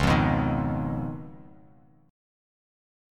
Db9 Chord
Listen to Db9 strummed